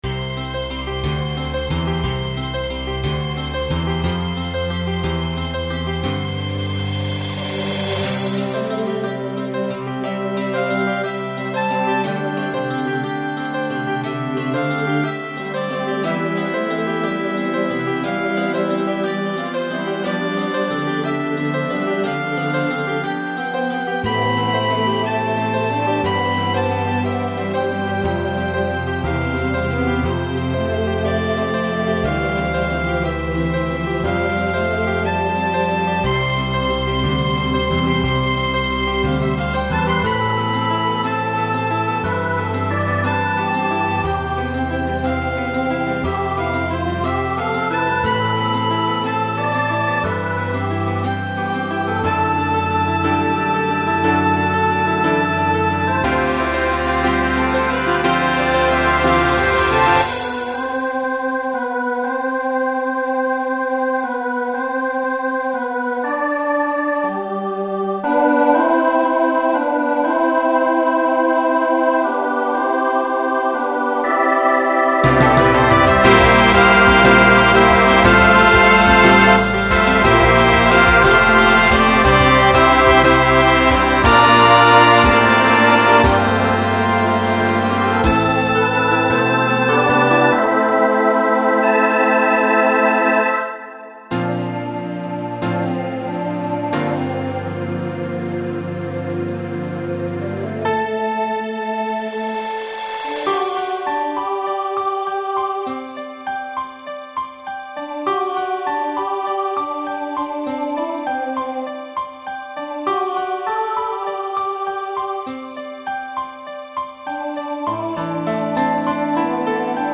2000s Show Function Ballad